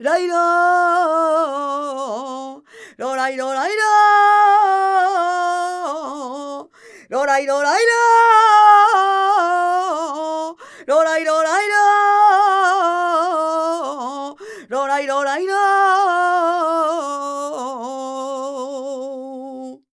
46b23voc-fm.aif